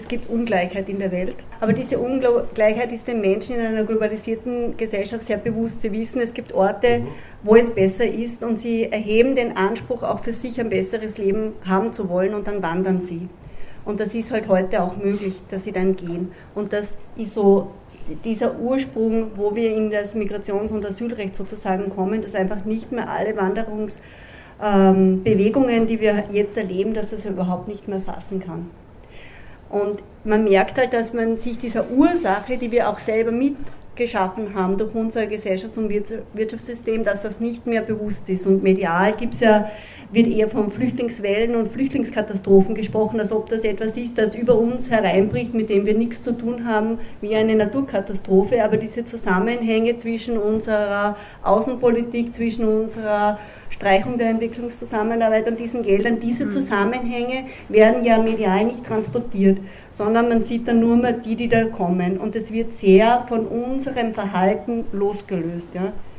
Das Quartals.Gespräch ist „Wissenschaft zum Angreifen“ – so nennt sich das neue Diskussionsformat im Solaris in Linz, initiiert von der Katholischen Privat-Universität Linz (KU) / Theologisch-praktischer Quartalschrift, dem Institut Pastorale Fortbildung, dem Bildungszentrum Haus der Frau sowie der Personalentwicklung der Diözese Linz.